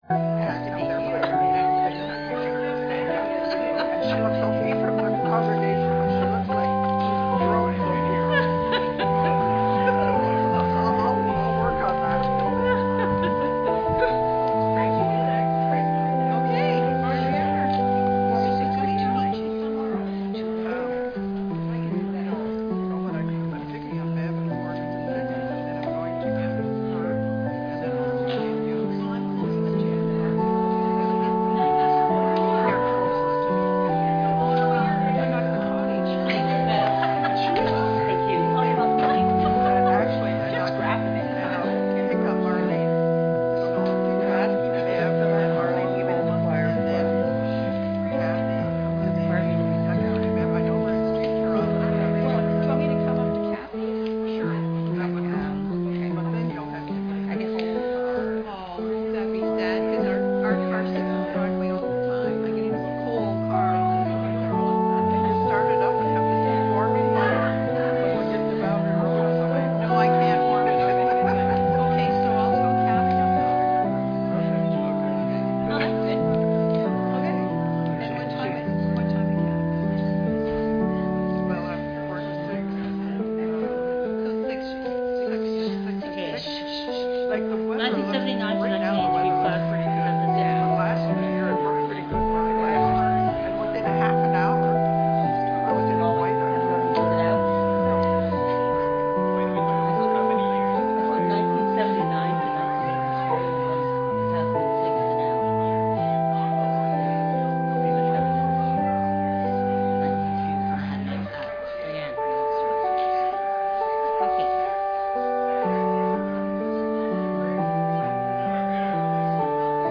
Passage: Isaiah 2:1-5, Matthew 24:36-44 Service Type: Standard « Sunday Nov 23